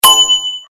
ASDAS CAMPANA CARME
Ambient sound effects
asdas_campana_carme.mp3